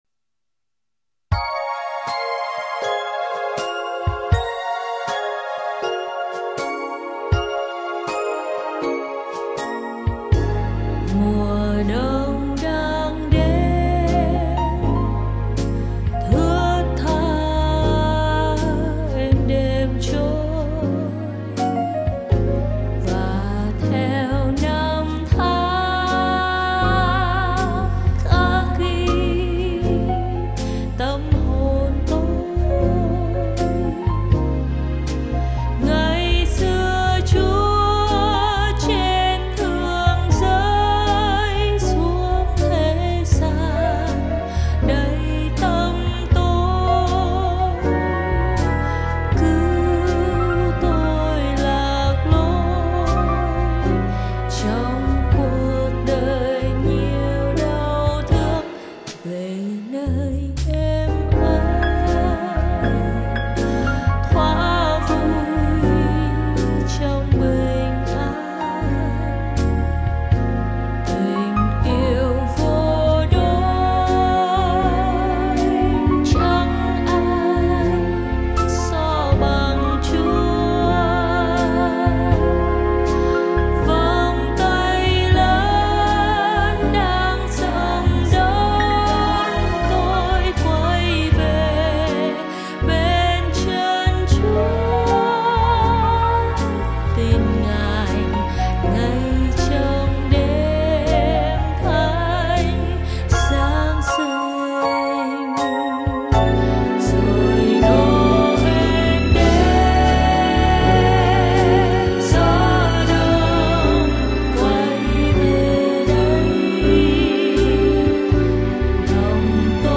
Nhạc Thánh